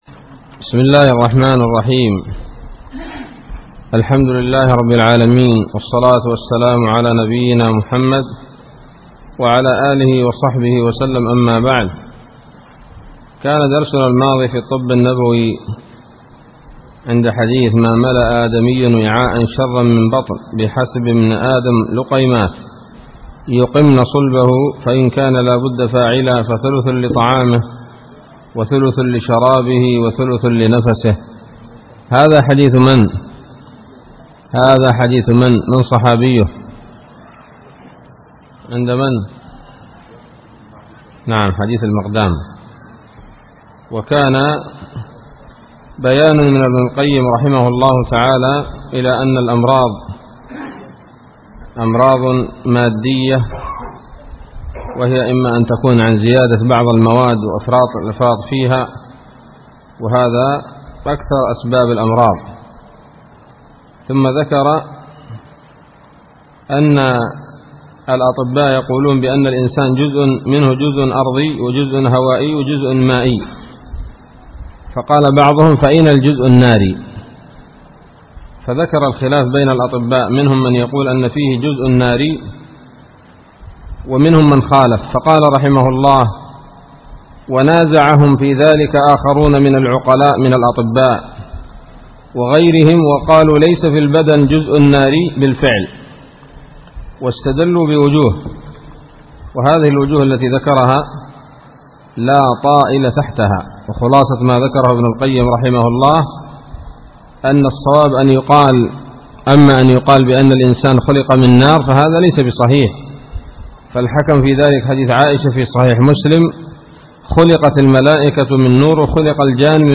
الدرس السادس من كتاب الطب النبوي لابن القيم